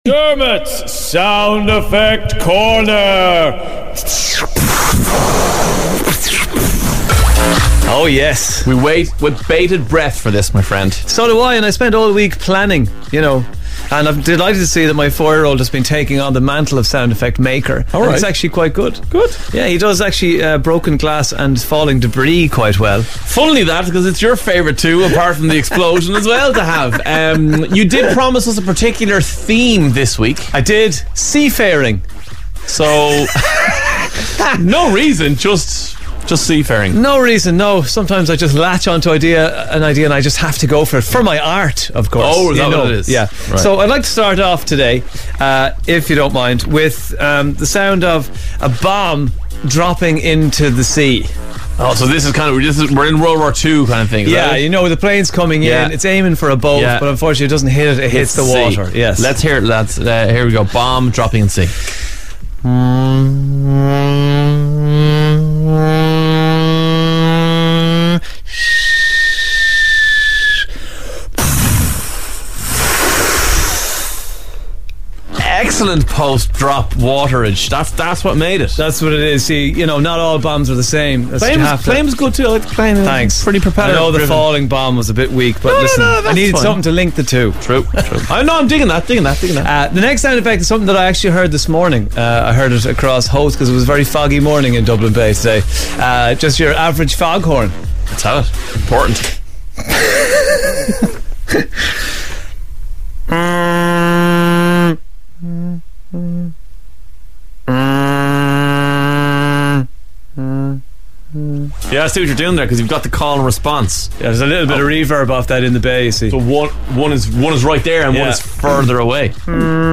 A submarine crashing into a whale